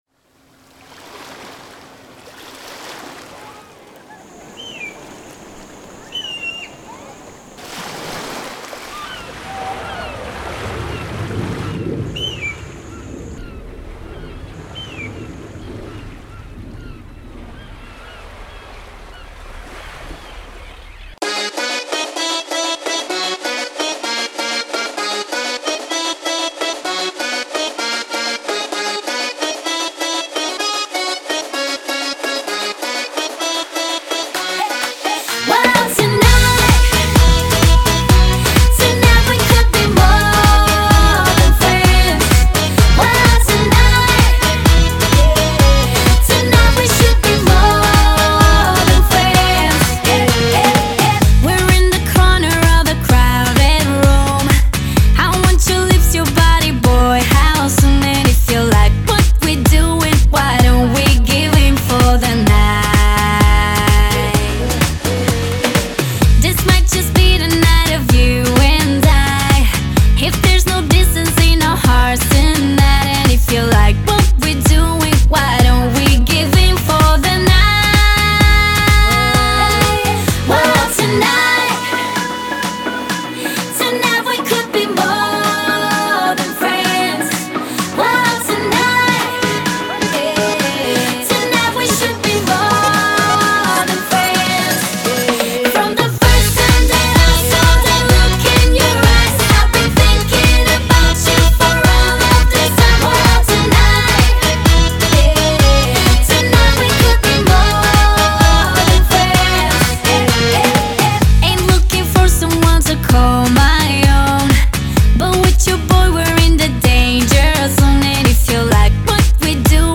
Веселая музыка)))